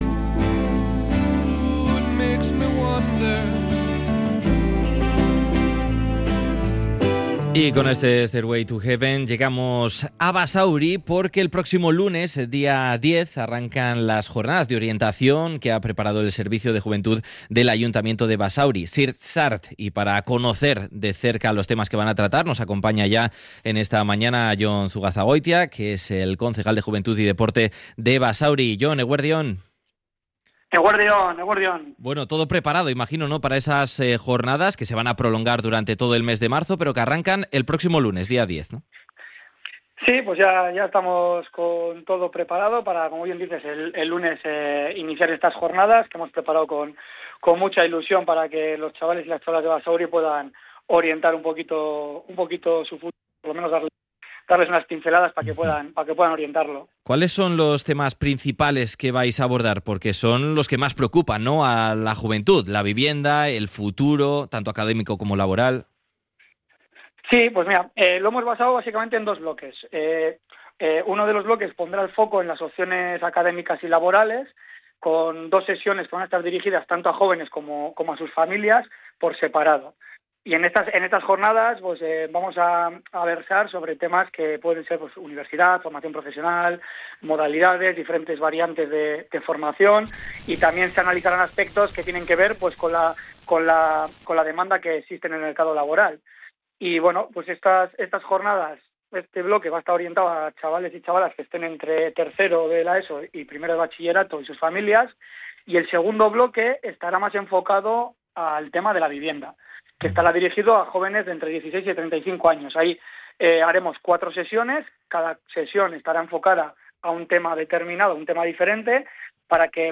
El próximo lunes, 10 de marzo, comenzarán las Jornadas de Orientación organizadas por el Servicio de Juventud del Ayuntamiento de Basauri, ZirtZart El concejal de juventud y deporte, Jon Zugazagoitia, ha destacado en Radio Popular – Herri Irratia la importancia de estas sesiones, que se extenderán durante todo el mes de marzo.